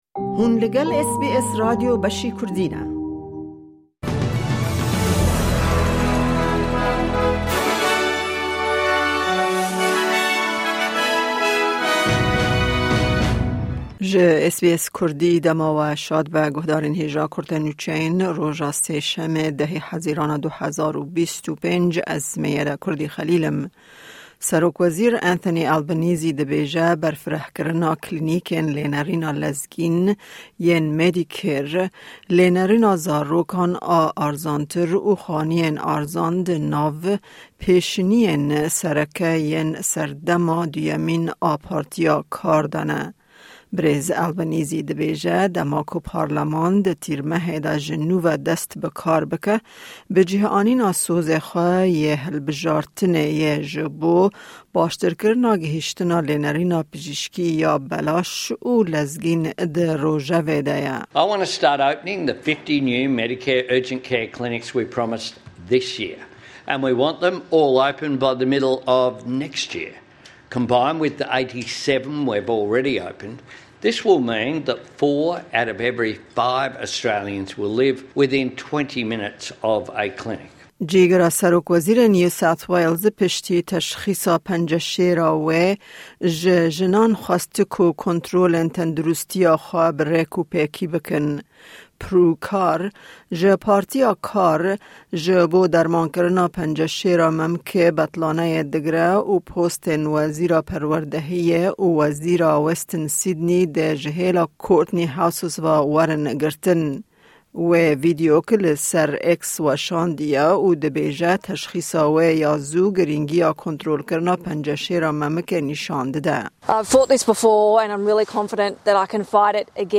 Kurte Nûçeyên roja Sêşemê 10î Hezîrana 2025